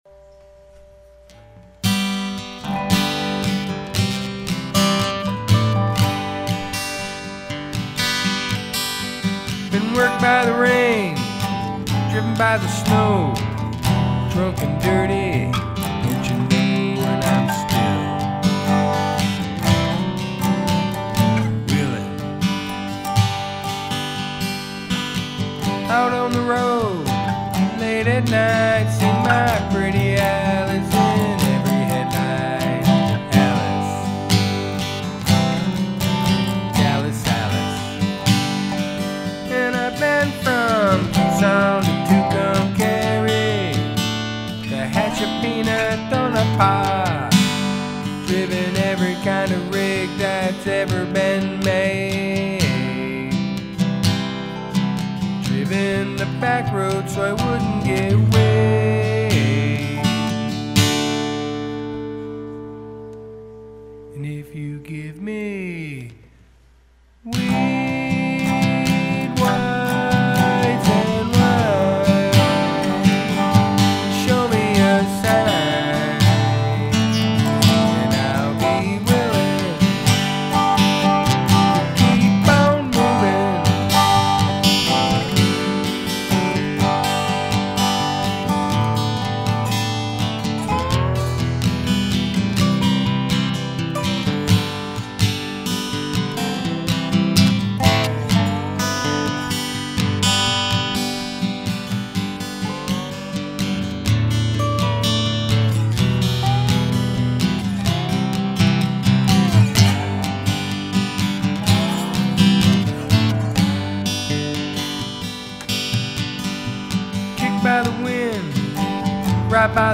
just trying to get the guitar to equal.